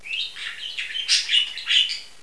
Wellensittich Songs: Musik für unsere Ohren
Hier jetzt einige "Songs" von unseren Sittichen, beim Schlummerstündchen und während des Spielens aufgenommen.
tschirp5.wav